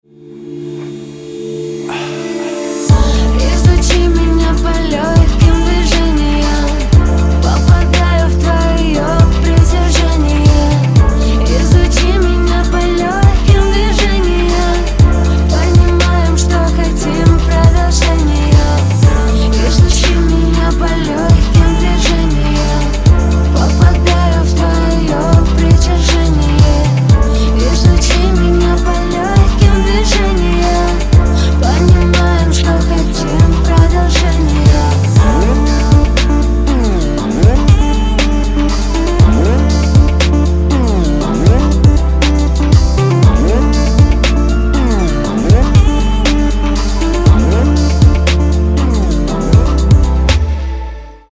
• Качество: 256, Stereo
поп
женский вокал
спокойные
RnB
сексуальные